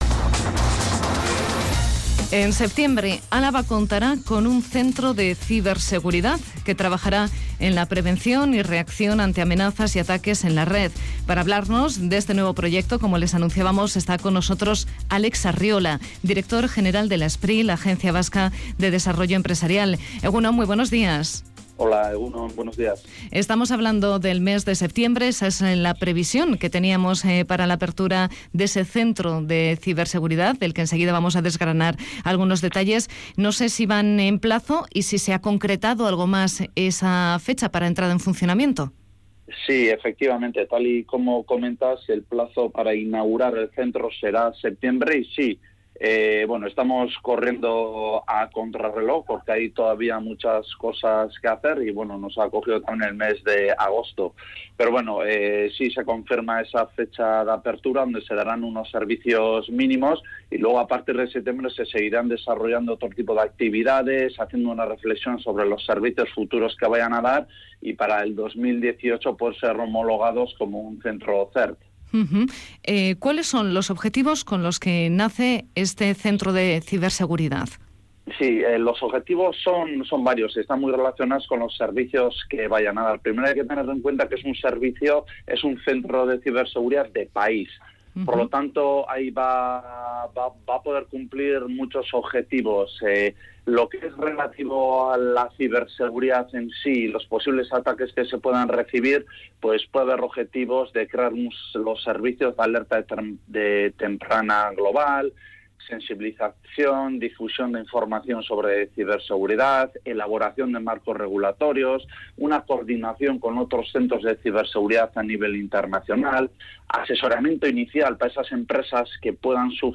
Audio: Entrevista a Álex Arriola, director general de la SPRI
Radio Vitoria ARABA_GAUR_8H ''El Centro de Ciberseguridad va a dar un espaldarazo a Miñano'' Última actualización: 11/08/2017 09:48 (UTC+2) Álex Arriola, director general de la SPRI, ha estado en la primera edición de 'Araba Gaur', en Radio Vitoria. Según ha confirmado, el Centro Vasco de Ciberseguridad abrirá sus puertas en septiembre en Miñano.